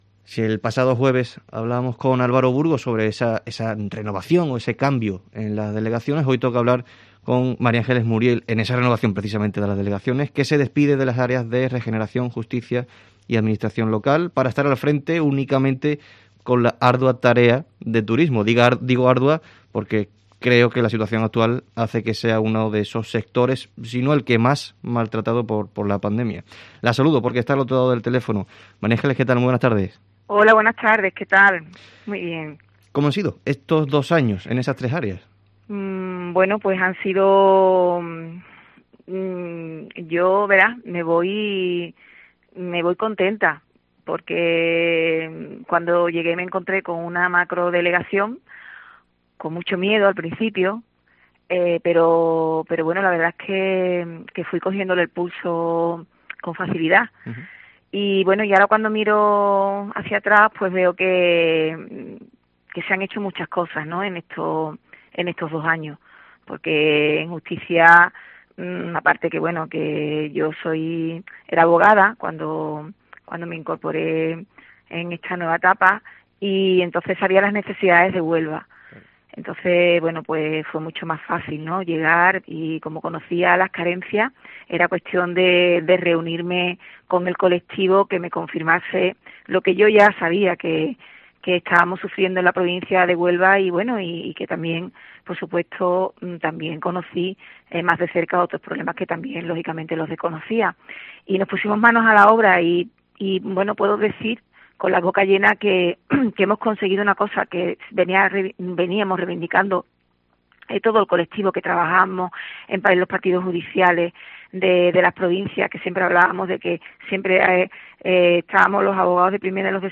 En el Herrera en COPE Huelva de este lunes 25 de enero hemos charlado con María Ángeles Muriel, delegada de Turismo de la Junta de Andalucía en Huelva.